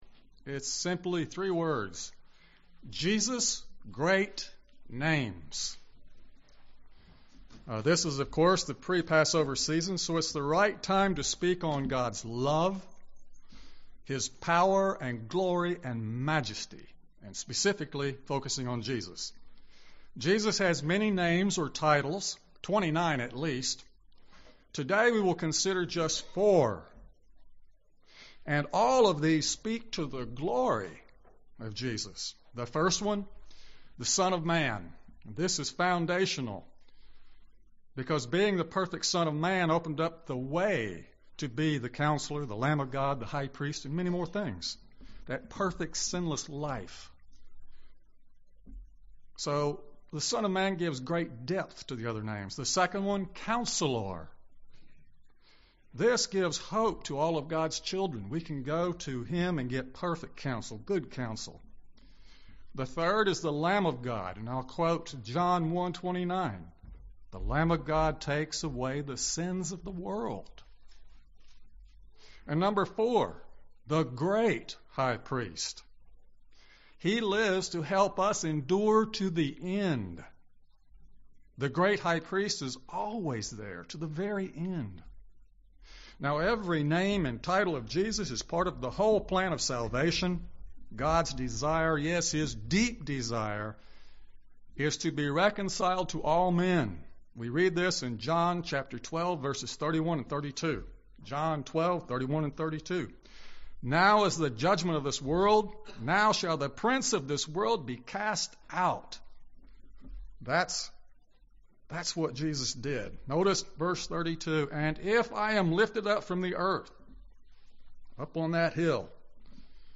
Sermons
Given in Gadsden, AL